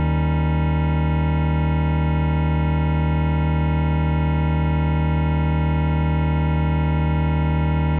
eb7-chord.ogg